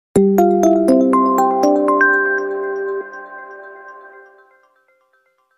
This is my new Intro. (LG 2014-2020 Startup Animation) (720p50 version)